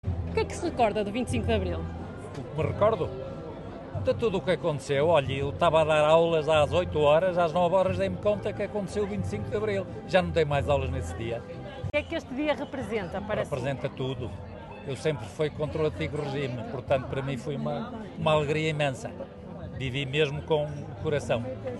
Terminada a Assembleia Municipal extraordinária, que assinalou o 25 de Abril, encontrámos, numa esplanada ali perto, um grupo de amigos que nos contou como foi vivido aquele dia.